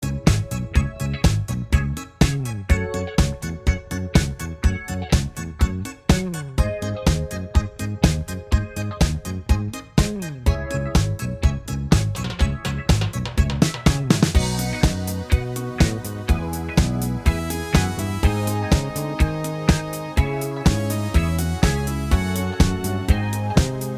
Minus Main Guitar Disco 3:37 Buy £1.50